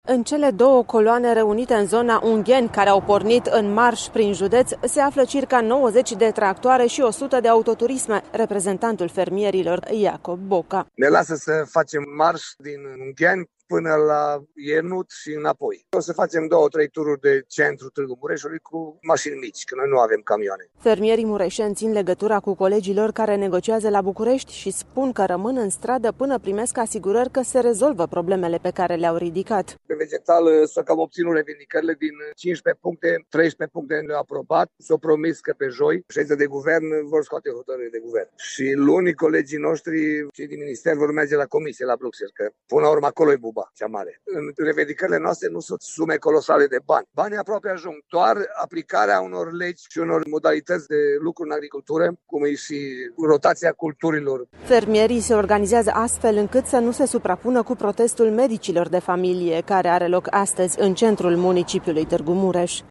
Şi în Mureş fermierii rămân mobilizați pe drumuri ca formă de protest – două coloane de tractoare şi autoturisme şi-au dat întâlnire lângă Târgu Mureş şi au pornit, în coloană într-un marş, relatează